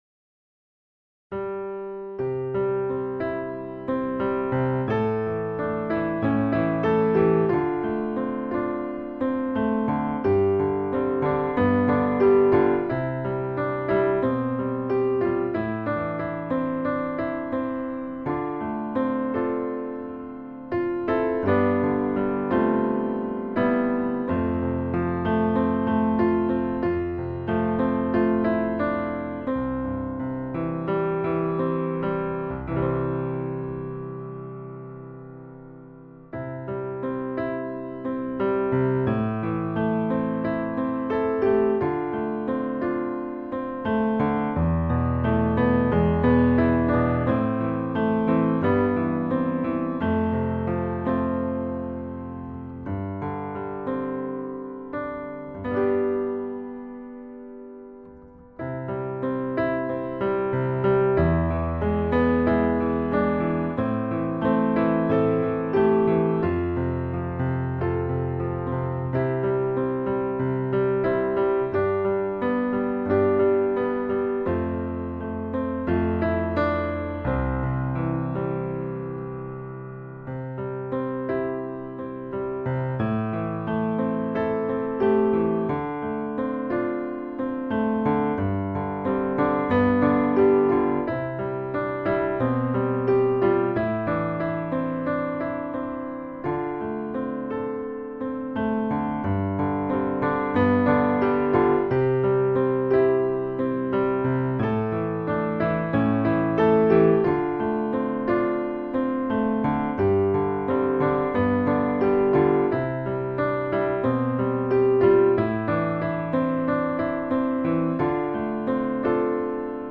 du piano seul (karaoké) de la version 2016